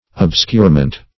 Search Result for " obscurement" : The Collaborative International Dictionary of English v.0.48: Obscurement \Ob*scure"ment\ ([o^]b*sk[=u]r"ment), n. The act of obscuring, or the state of being obscured; obscuration.